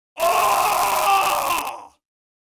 Screams Male 03
Screams Male 03.wav